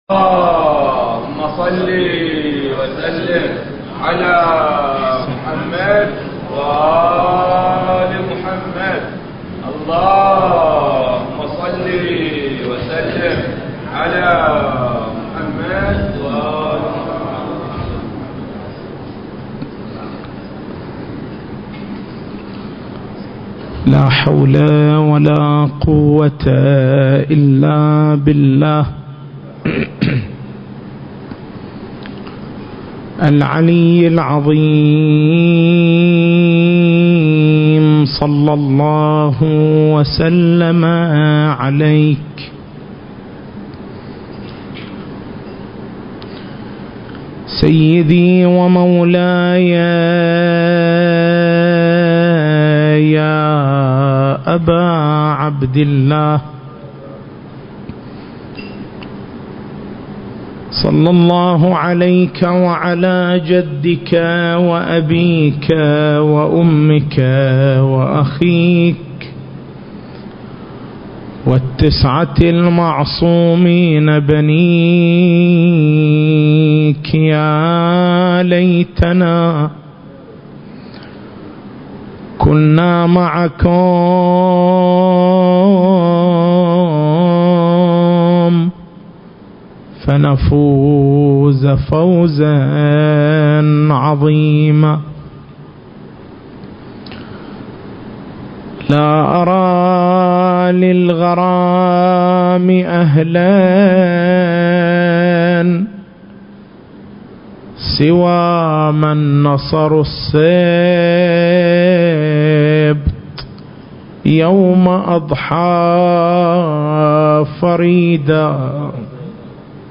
المكان: حسينية الحاج حبيب العمران